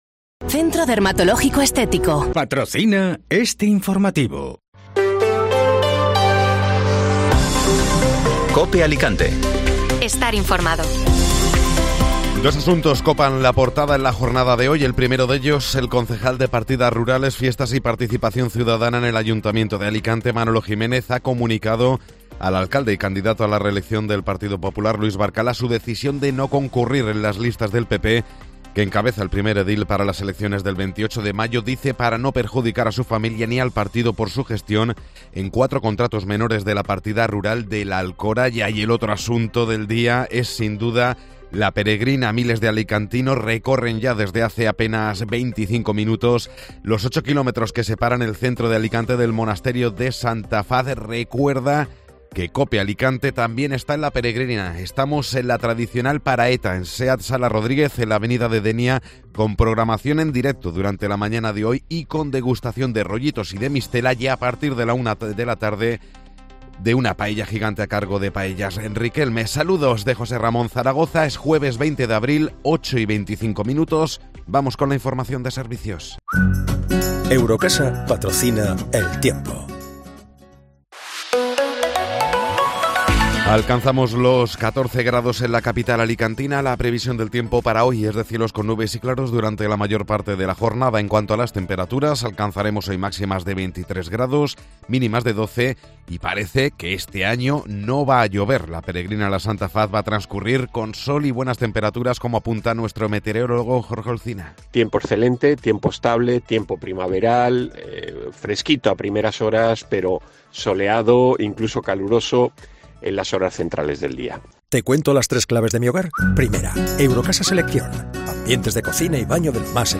Informativo Matinal (Jueves 20 de Abril)